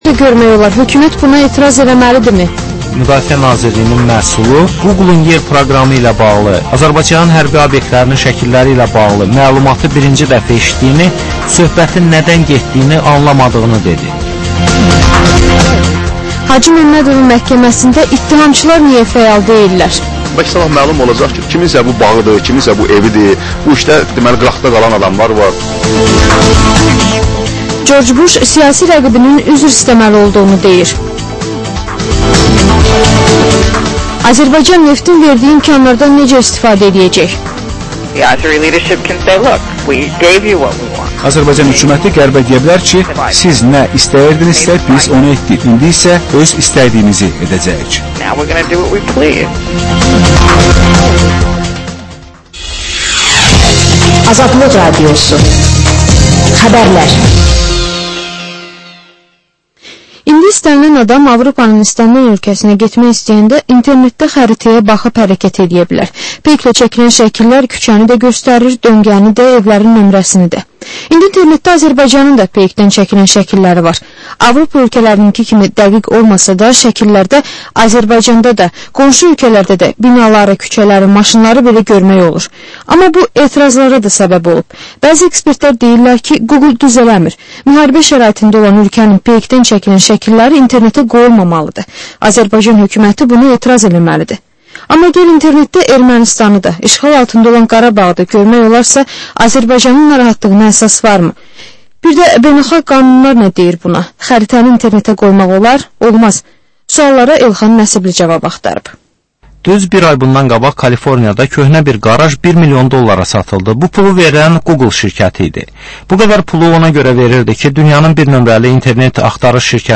Hadisələrin təhlili, müsahibələr və xüsusi verilişlər.